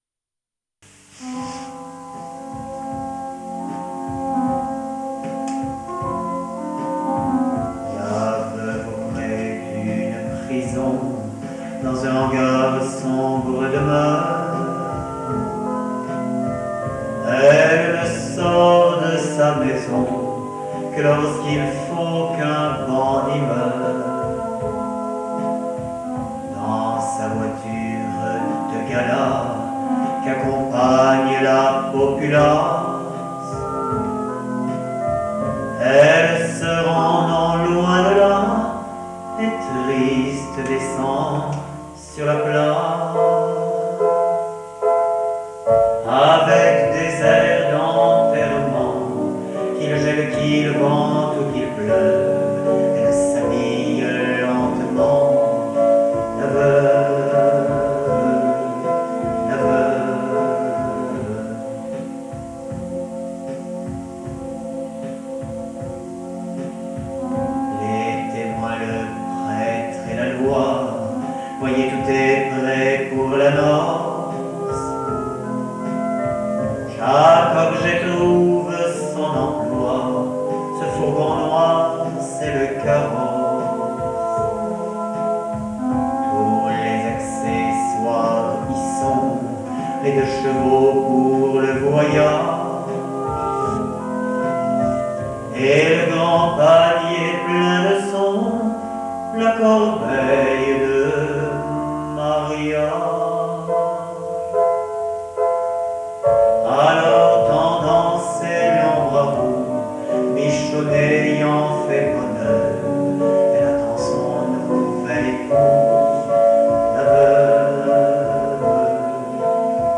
T�moignages en chansons La Veuve Tir�e du spectacle Chansons de rue Enregistr�e en public en 1983 Ce po�me �crit par Jules Jouy, chansonnier, �crivain, journaliste, peintre ( 1855-1893) , est une d�nonciation de la peine de mort. La veuve �tait le surnom donn� � la guillotine. le 17 juin 1939 : derni�re ex�cution publique en France le 10 septembre 1977 : dernier guillotin� � Marseille le 18 septembre 1981 : abolition de la peine de mort La_veuve.mp3 Sommaire